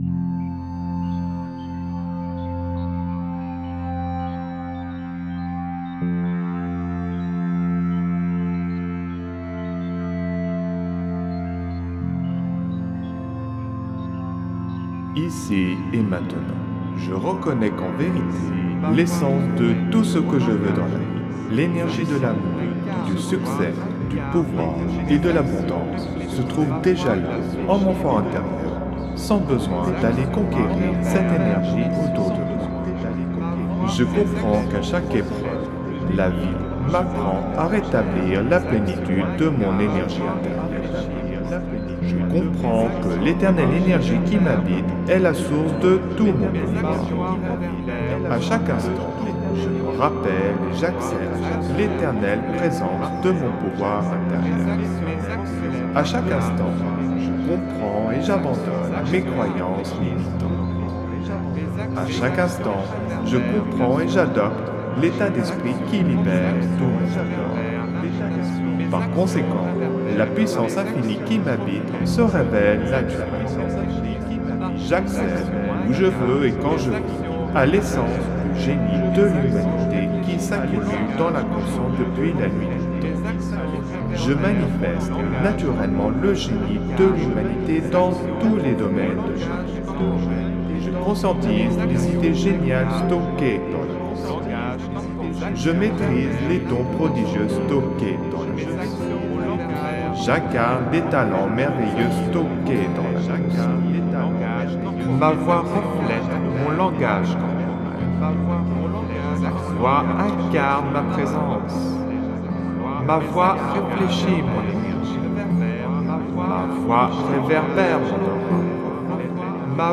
(Version ÉCHO-GUIDÉE)
Alliage ingénieux de sons et fréquences curatives, très bénéfiques pour le cerveau.
Pures ondes gamma intenses 87,02 Hz de qualité supérieure. Puissant effet 3D subliminal écho-guidé.